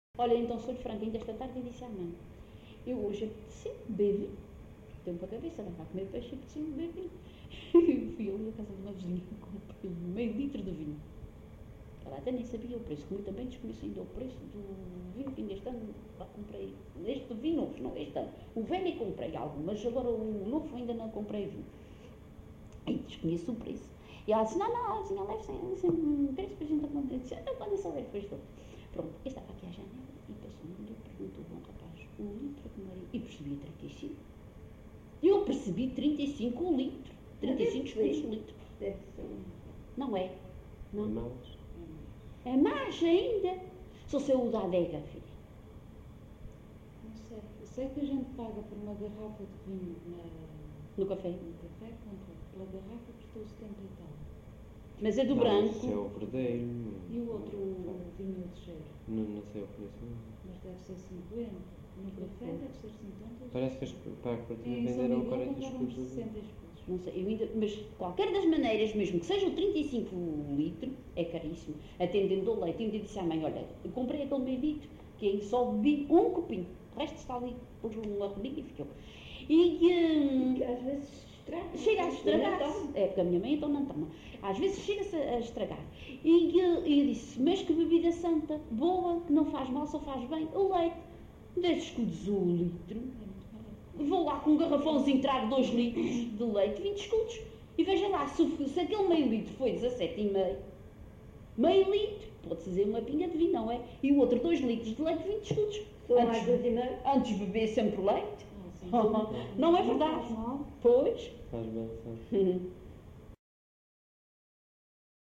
LocalidadeCarapacho (Santa Cruz da Graciosa, Angra do Heroísmo)